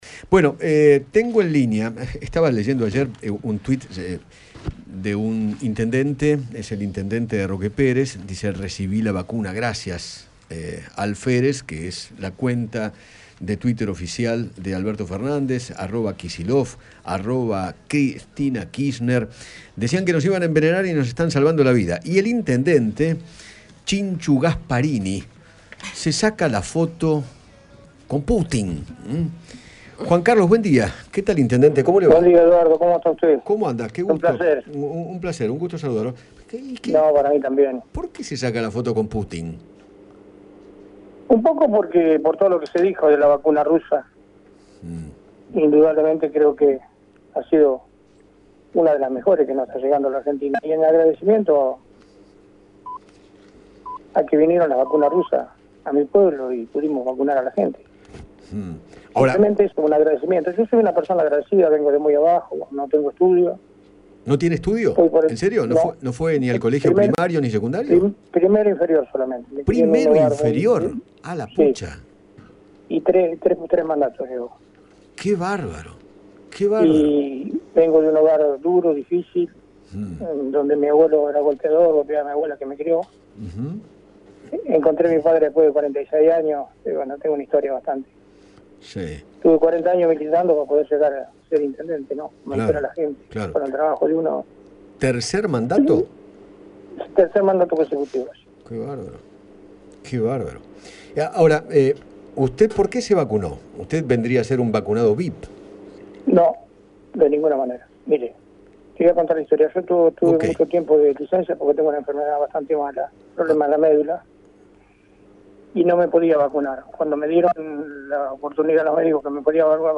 Juan Carlos Gasparini conversó con Eduardo Feinmann acerca de la razón que lo llevó a ir a vacunarse con el cuadro del presidente de Rusia. Además, contó su increíble historia de vida.